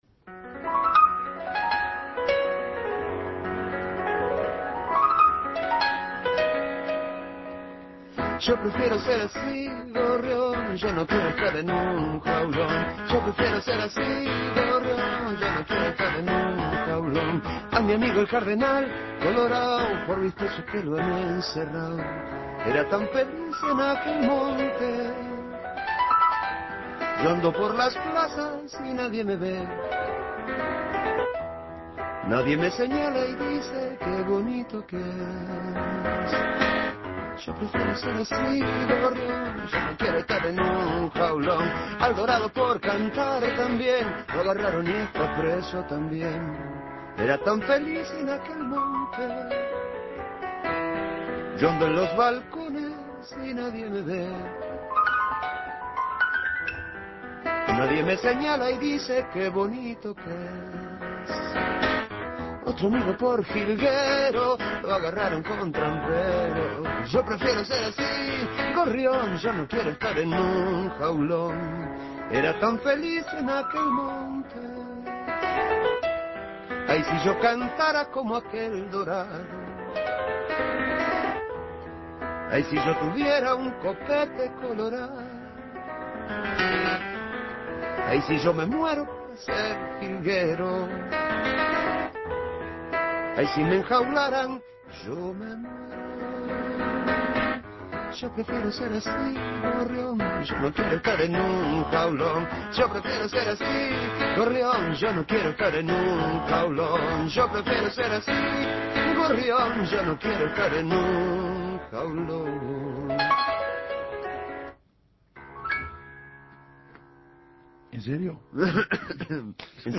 teclado en mano